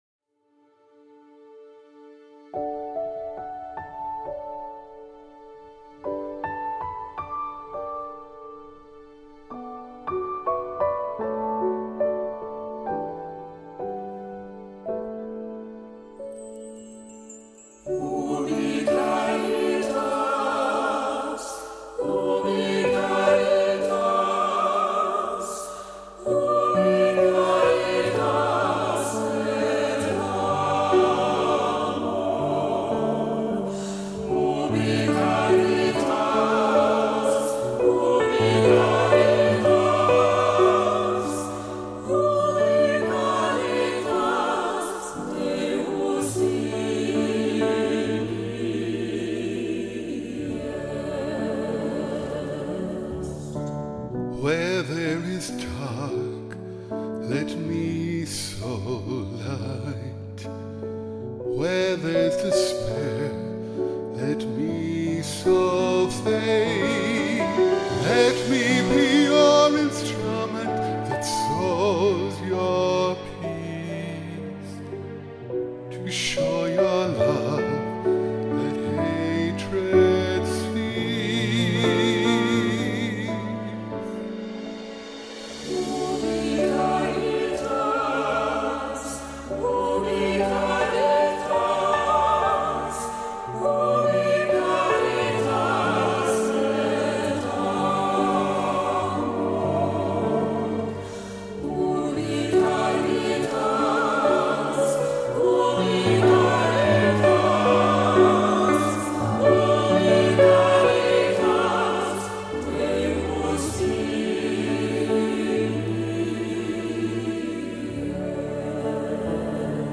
Voicing: "SATB","Assembly"